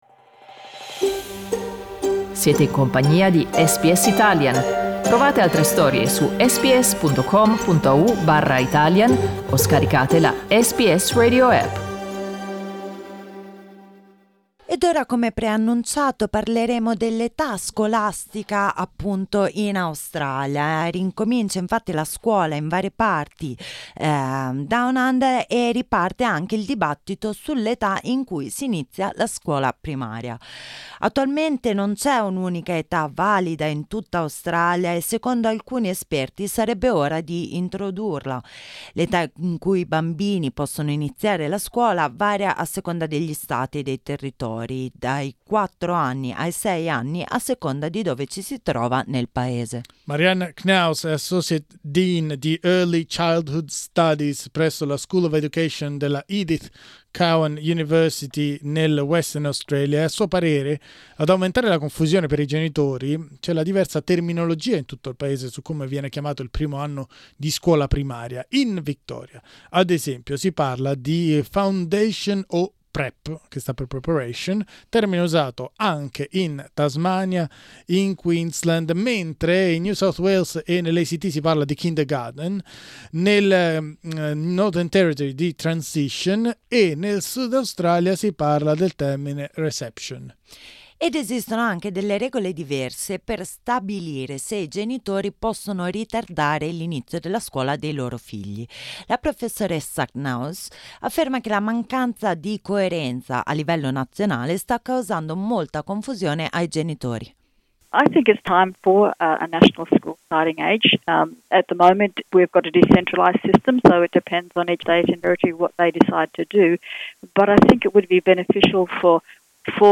Ascolta il nostro servizio: LISTEN TO A che età cominciare il percorso scolastico?